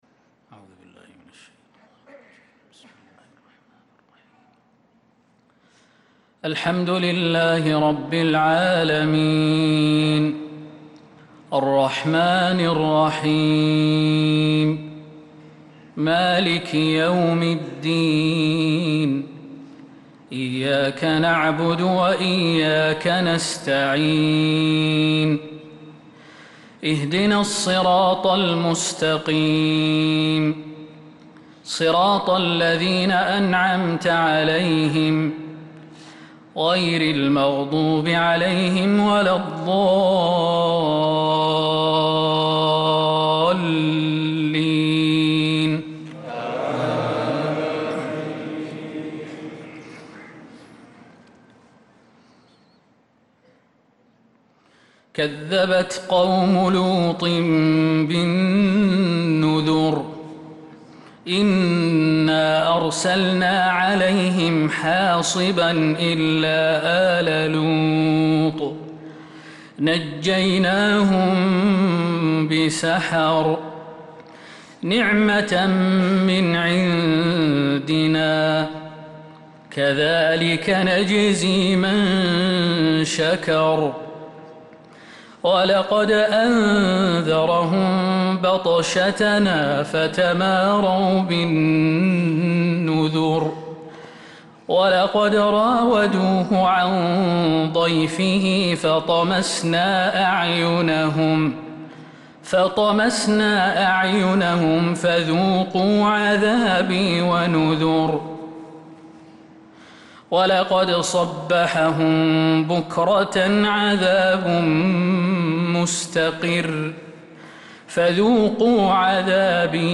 صلاة العشاء للقارئ خالد المهنا 29 شوال 1445 هـ
تِلَاوَات الْحَرَمَيْن .